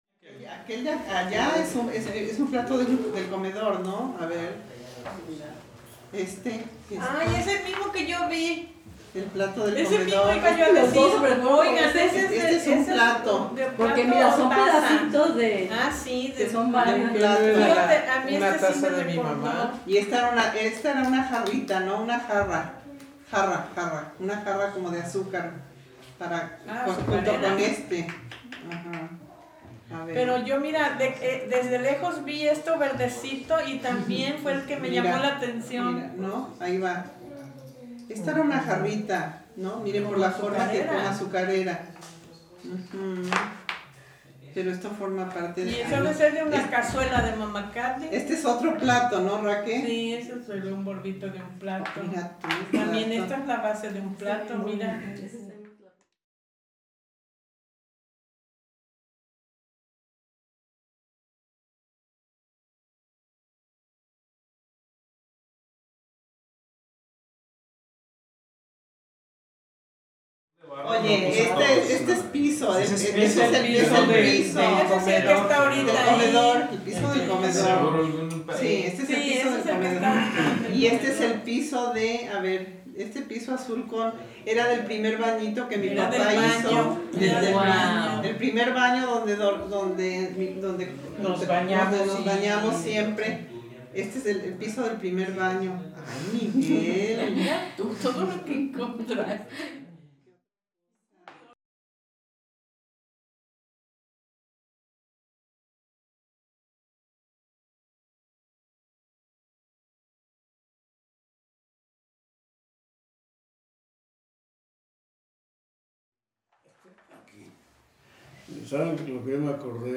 En una suerte de entrevista me compartieron los recuerdos que recuperaron al entrar en contacto con dichos pequeños fragmentos de su historia. Entrevista , 2017 Mezcla de sonido estéreo 10 min Objetos encontrados , 2014-2017 Piezas de barro, cerámica, vidrio, mica, plástico.